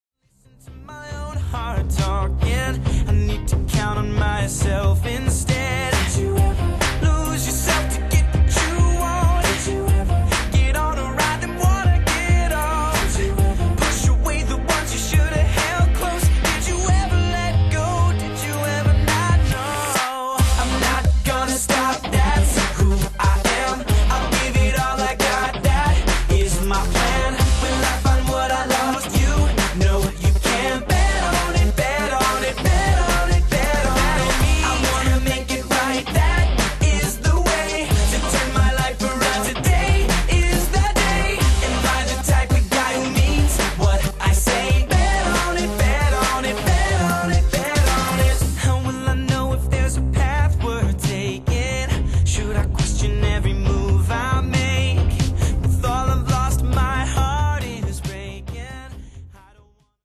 Media/Arrangement: CD Graphics Disc